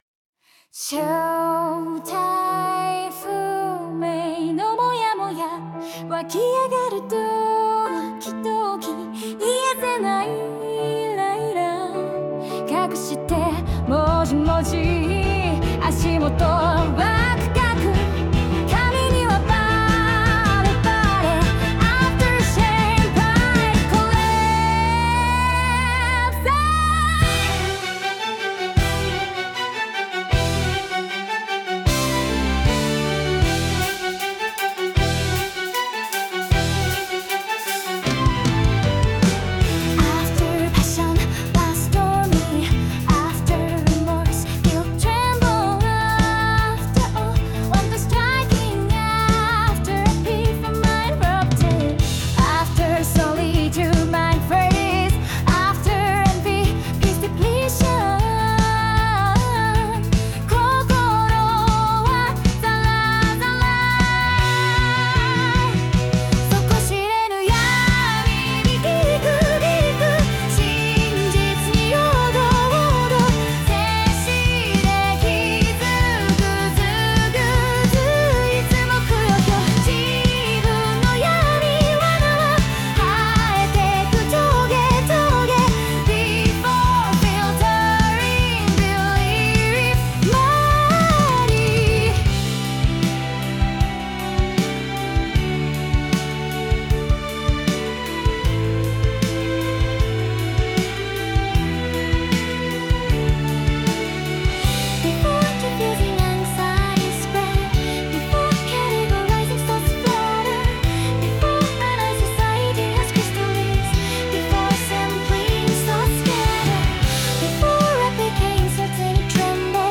ノリノリのリズムで、聴いているうちに「こんな自分でも変われるかも」と思えるように制作しました。